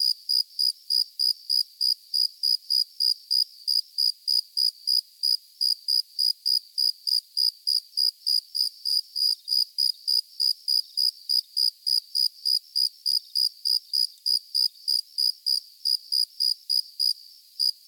57e0746fe6 Divergent / mods / Soundscape Overhaul / gamedata / sounds / ambient / soundscape / insects / insectnight_16.ogg 159 KiB (Stored with Git LFS) Raw History
insectnight_16.ogg